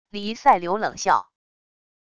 黎塞留冷笑wav音频